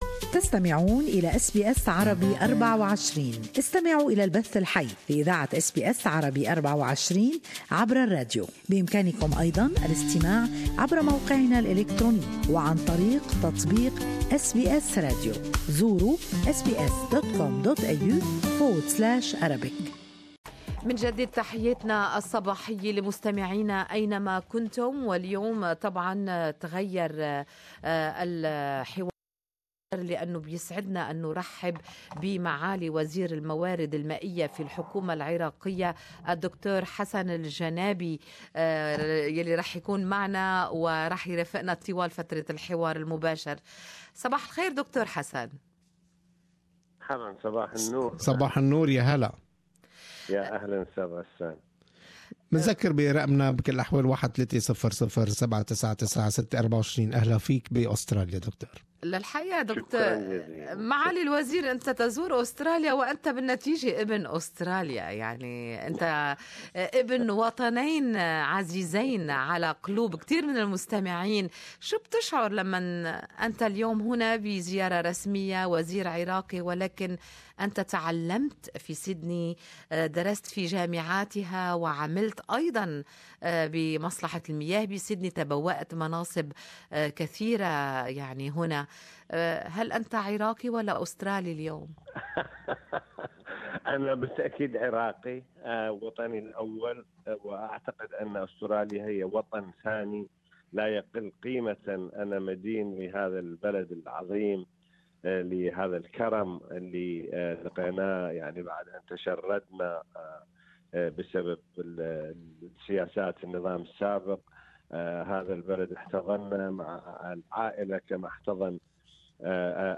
يزور أستراليا حاليا وزير الموارد المائية العراقي الدكتور حسن الجنابي يلتقي خلالها عددا من المسؤولين الأستراليين. وخص الوزير الجنابي البرنامج الصباحي Good Morning Australia بلقاء مباشر وحصري على اس بي اس عربي 24 تحدث فيه عن أزمة توفر المياه في العراق والشح الذي تتعرض له العراق في السنوات الأخيرة.